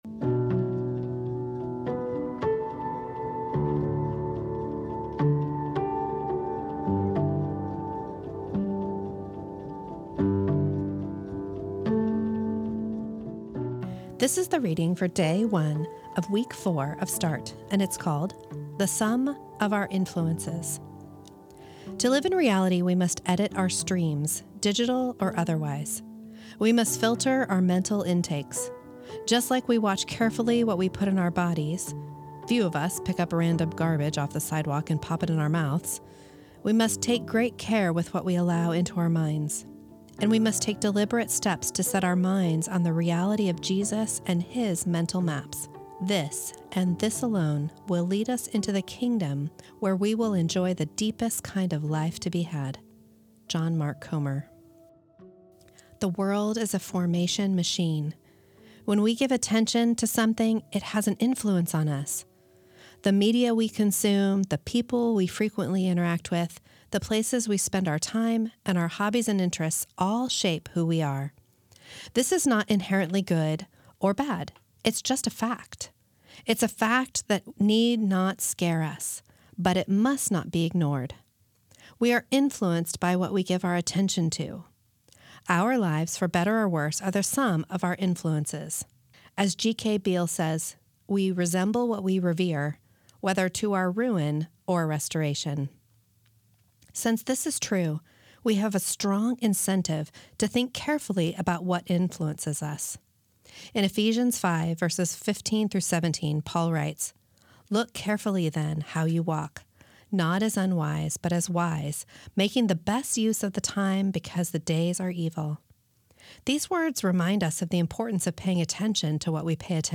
This is the audio recording of the first reading of week six of Start, entitled The Sum of Our Influences.